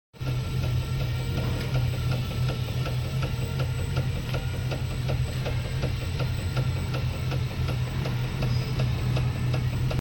Suspension Noise Tester Chasis Sound Effects Free Download